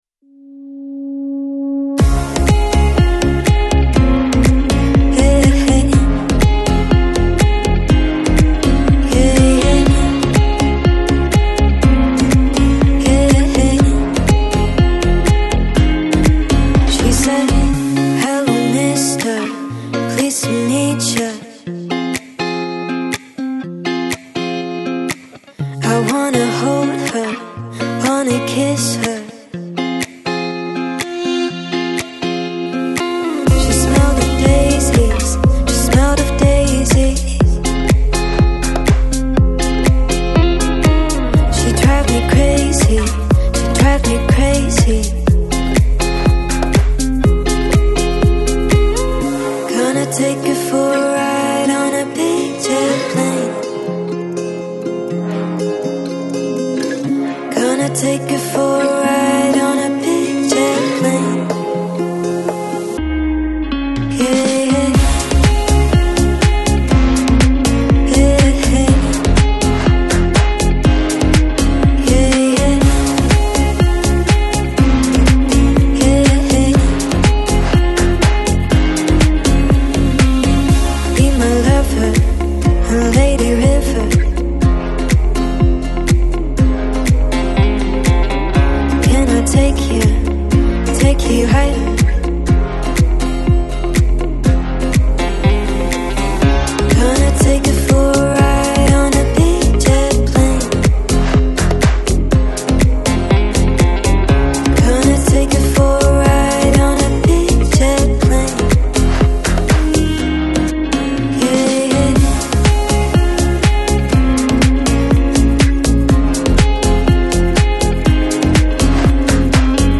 Lounge, Chill Out, Pop, Electronic Носитель